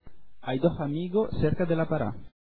diventa, in Andalucia ed Estremadura,